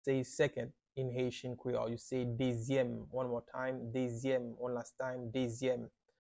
“Second” in Haitian Creole – “Dezyèm” pronunciation by a native Haitian teacher
“Dezyèm” Pronunciation in Haitian Creole by a native Haitian can be heard in the audio here or in the video below: